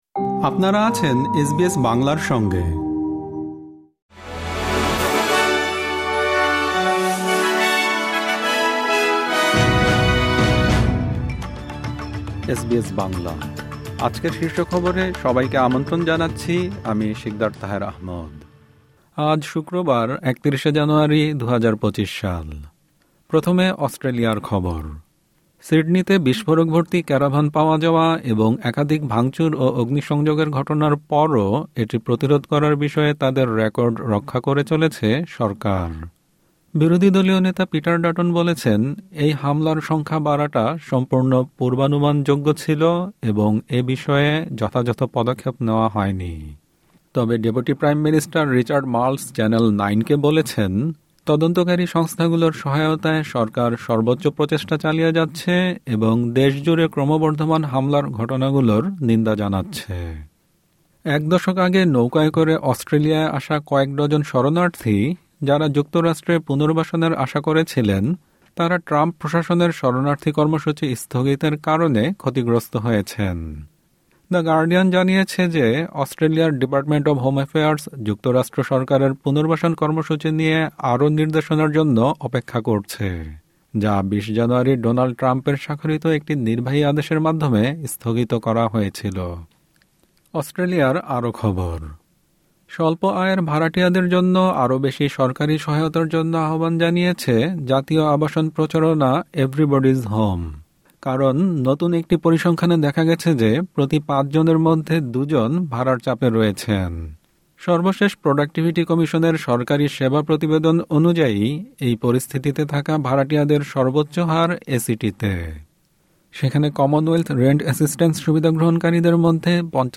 এসবিএস বাংলা শীর্ষ খবর: ৩১ জানুয়ারি, ২০২৫